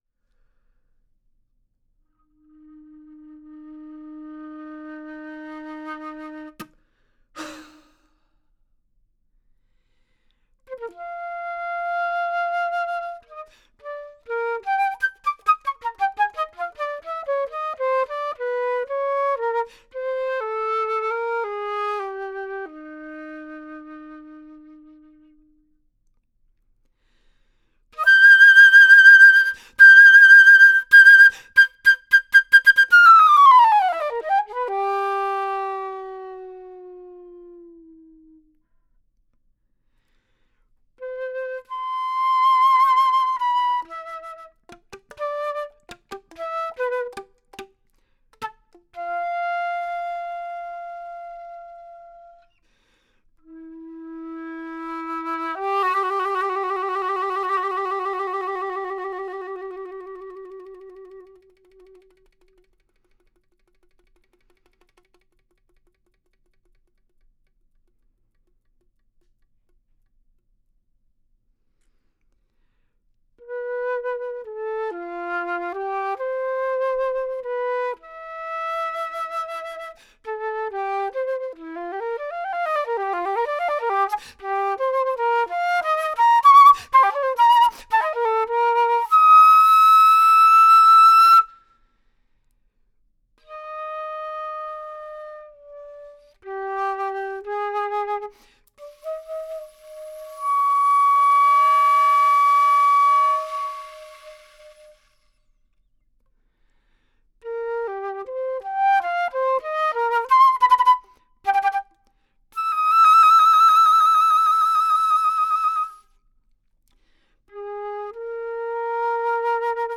solo flute (2014)